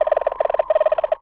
cartoon_electronic_computer_code_12.wav